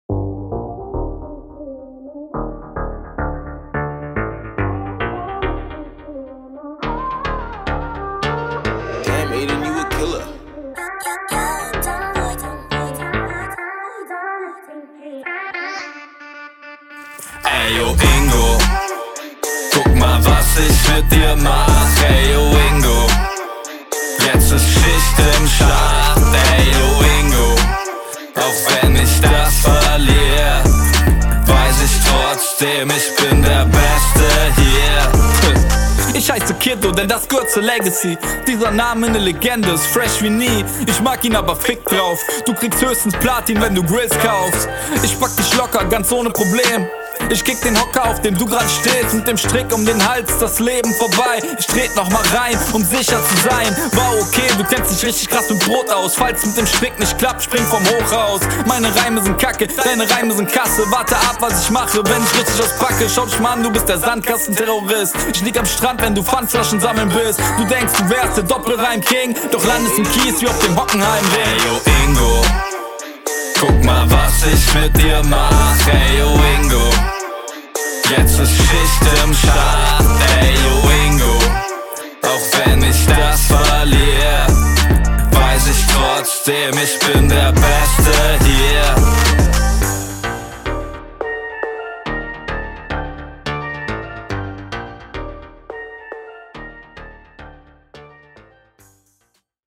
Hier schön mit dem Gesangspart gekontert.